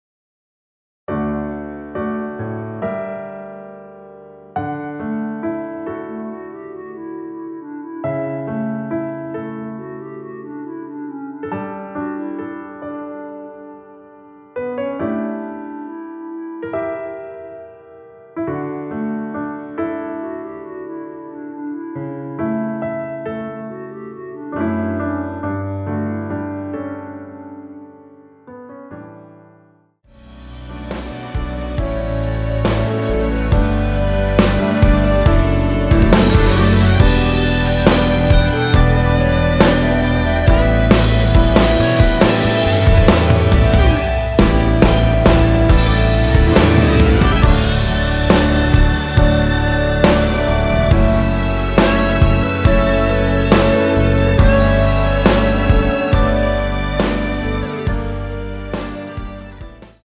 노래 들어가기 쉽게 전주 1마디 만들어 놓았습니다.(미리듣기 확인)
원키에서(-2)내린 멜로디 포함된 MR입니다.
앞부분30초, 뒷부분30초씩 편집해서 올려 드리고 있습니다.